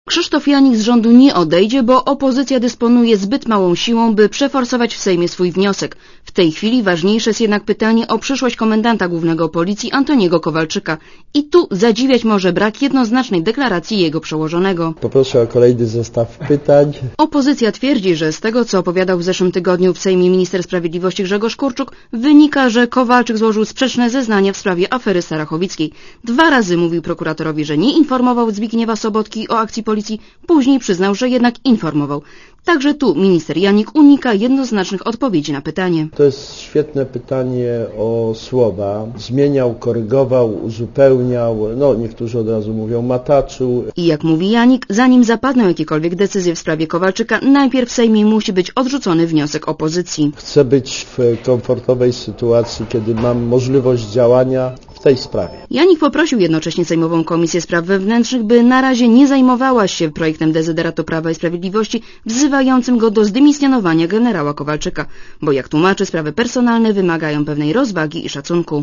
Posłuchaj relacji reporterki Radia Zet (267 KB)